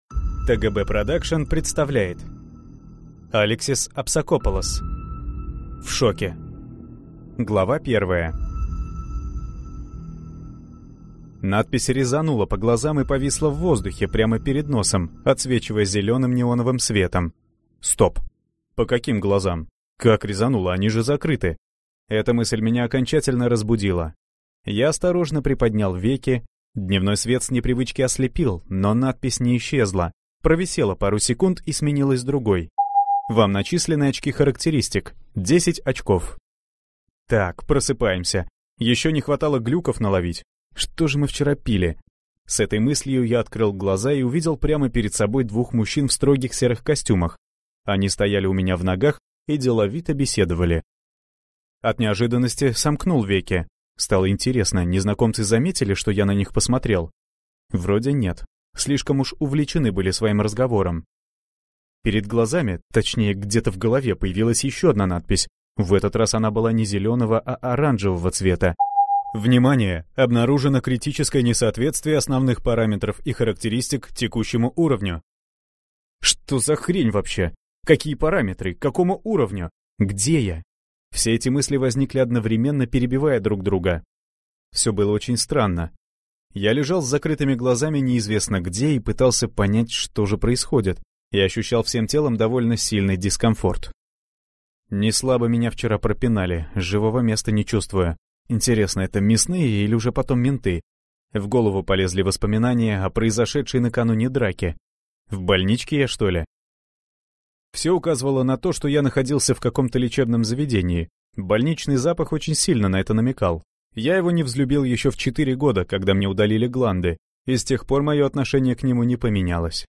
Аудиокнига В шоке | Библиотека аудиокниг